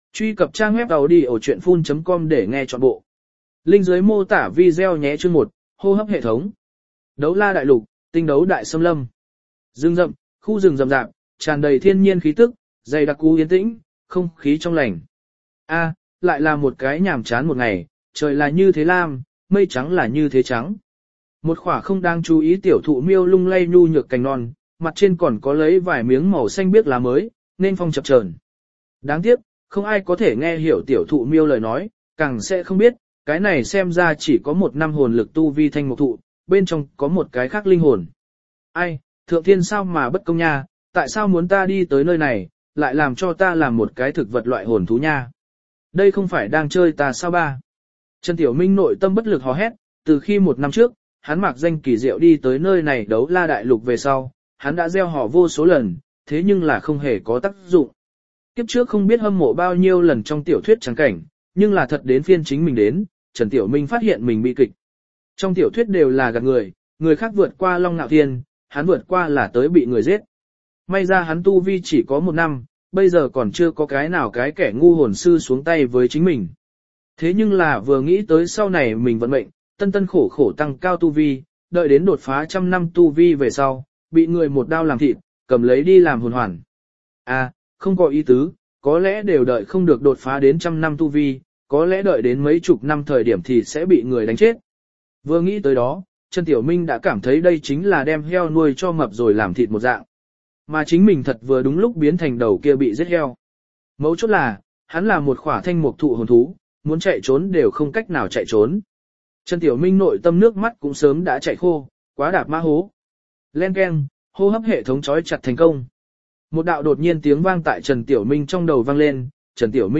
Đấu La Chi Chư Thiên Thăng Cấp Audio - Nghe đọc Truyện Audio Online Hay Trên AUDIO TRUYỆN FULL